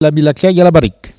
Langue Maraîchin
Patois - archives
Catégorie Locution